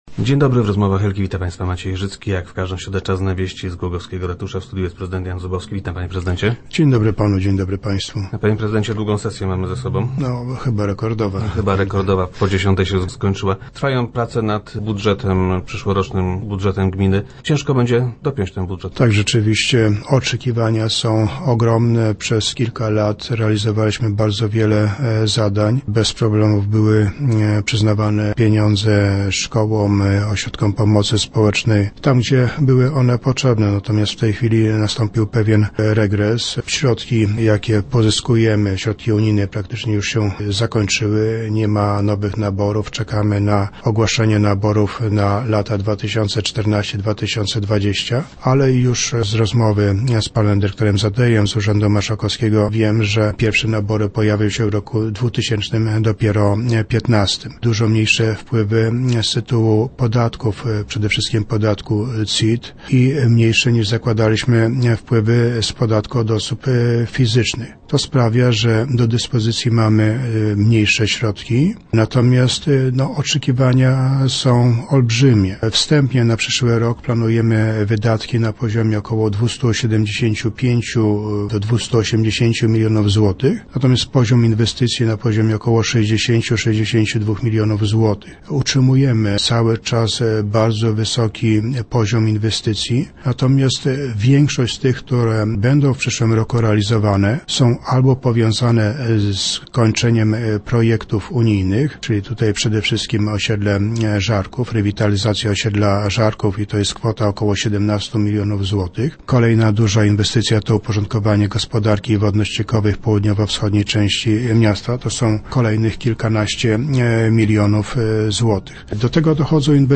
Jego projekt musi być gotowy do połowy listopada. - Nie ukrywam, że trudno jest dopiąć ten budżet – twierdzi prezydent Jan Zubowski, który był gościem Rozmów Elki.